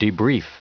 Prononciation du mot debrief en anglais (fichier audio)
Prononciation du mot : debrief